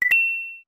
Sound effect from Super Mario RPG: Legend of the Seven Stars
Self-recorded using the debug menu
SMRPG_SFX_Coin.mp3